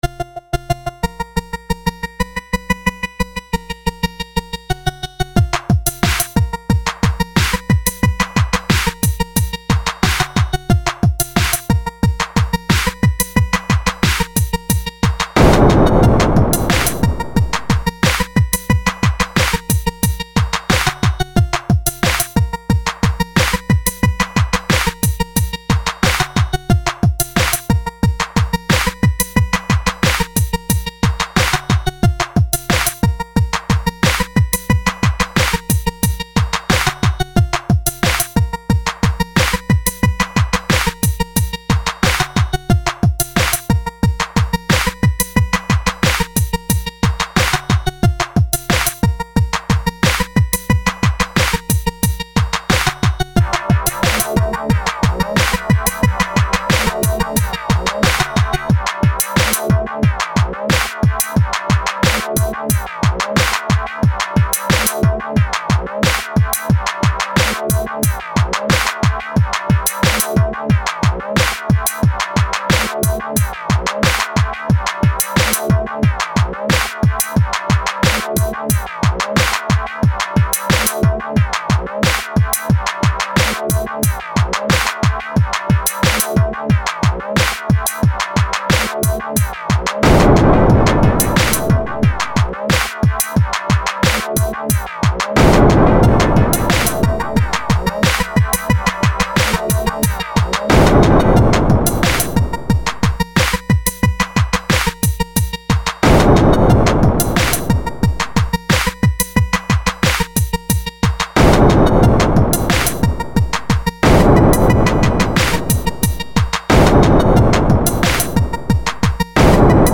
Простенькие минуса и сложные минуса!